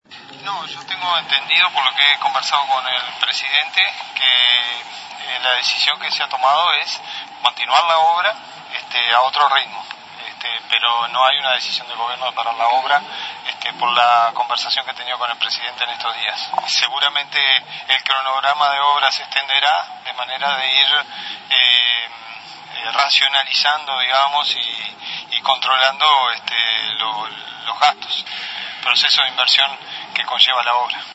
El vicepresidente de la República, Raúl Sendic, dijo a El Espectador que las obras del Antel Arena no se suspenden, sino que se extiende el cronograma.